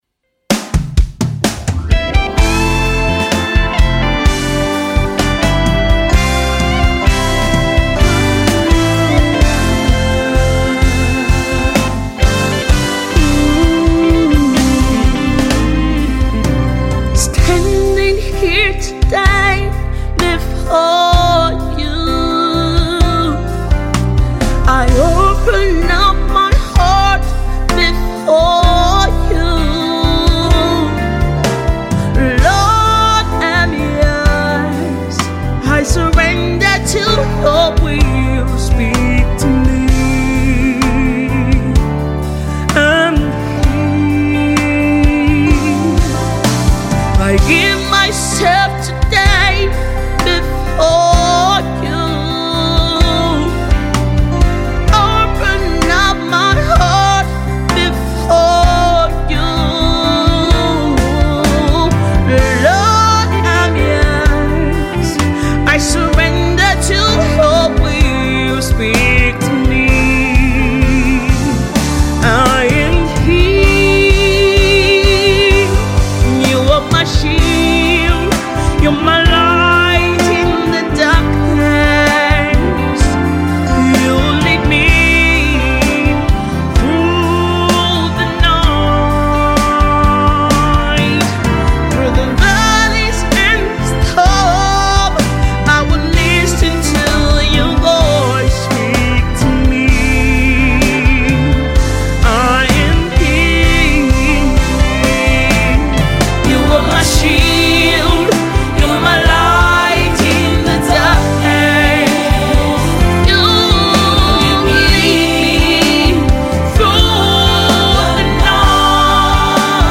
a total song of surrender and worship to the King of Glory.